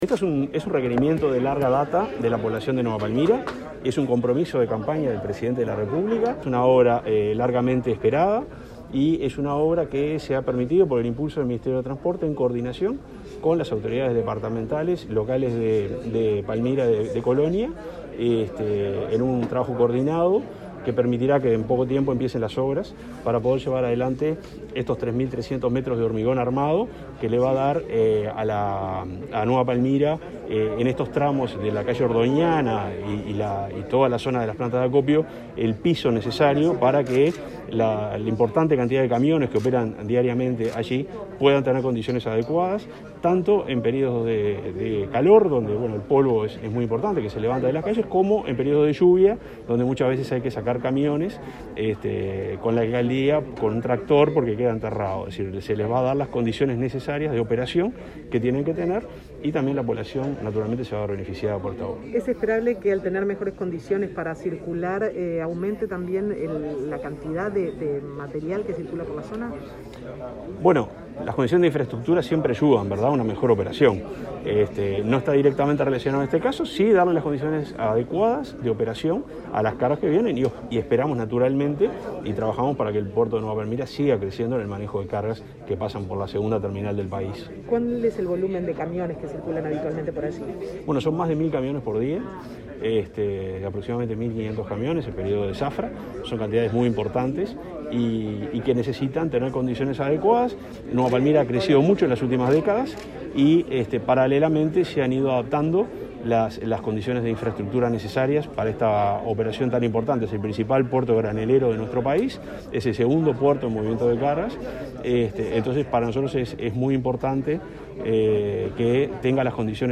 El subsecretario del MTOP, Juan José Olaizola, destacó durante la firma de un acuerdo con la Intendencia de Colonia para realizar 3.300 metros de obra vial en Nueva Palmira, que los trabajos mejorarán la operativa hacia el puerto local por donde pasan más de 1.000 camiones por día que necesitan la infraestructura adecuada para transportar cargas.